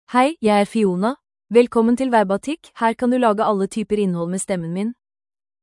Fiona — Female Norwegian Bokmål AI voice
Fiona is a female AI voice for Norwegian Bokmål (Norway).
Voice sample
Listen to Fiona's female Norwegian Bokmål voice.
Fiona delivers clear pronunciation with authentic Norway Norwegian Bokmål intonation, making your content sound professionally produced.